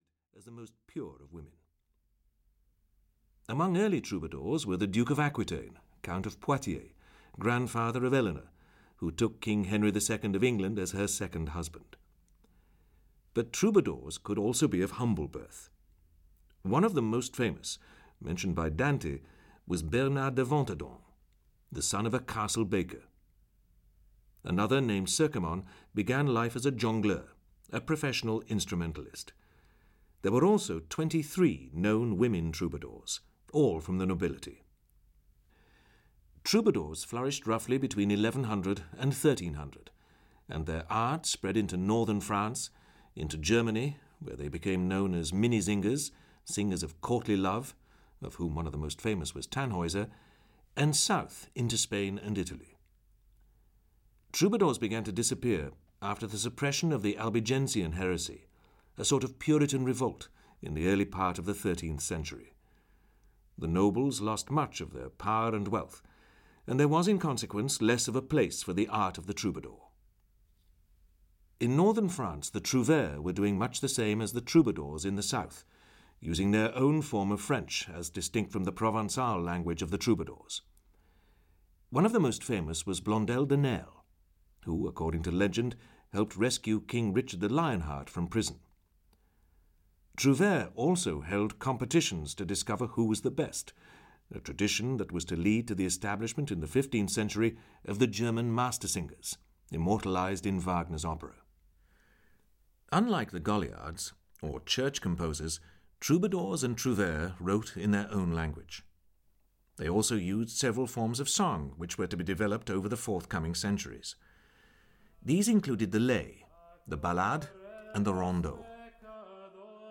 Ukázka z knihy
From Gregorian chant to Stravinsky and Gorecki, 150 musical excerpts illustrate the narrative.
• InterpretRobert Powell